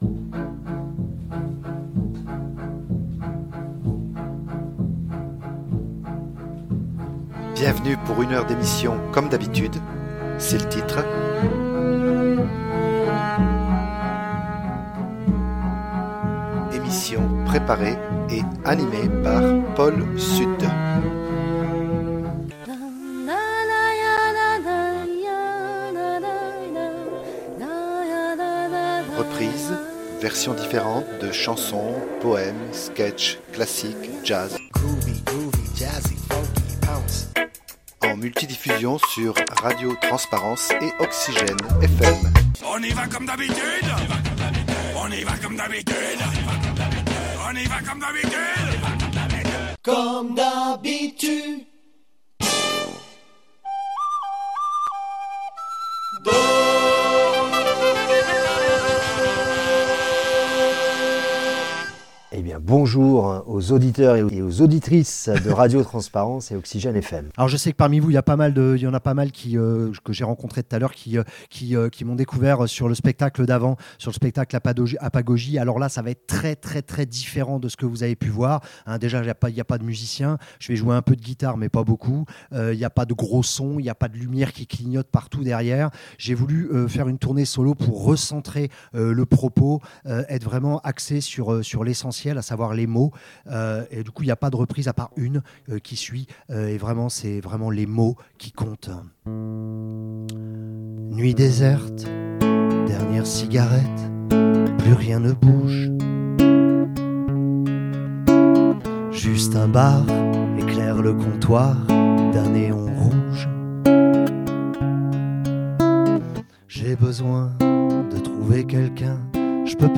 Après une tournée de près de 3 ans et 140 dates avec son spectacle Apagogie (dernier album) il était programmé le 23 novembre 2024 au Relais de Poche à Verniolle. Cette fois ci , finis les 5 musiciens sur scène , puisque la tournée qui débute – avec déjà plus de 60 concerts annoncés !- est un seul en scène , texte, voix et un peu de guitare !
Une bonne occasion de refaire une interview et de vous proposer de nouveaux titres , captés au Relais de Poche et quelques morceaux fameux revisités + des surp